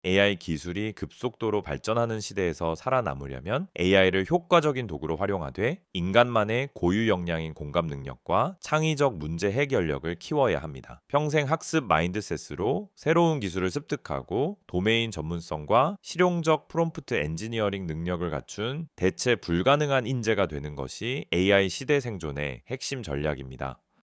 5. 요약본 바탕으로 오디오 생성(일레븐랩스 활용)